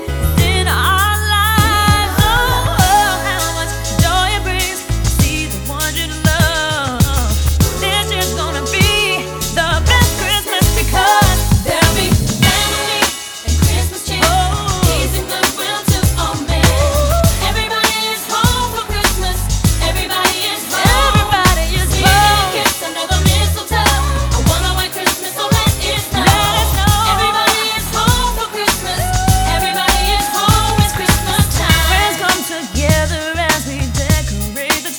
# Holiday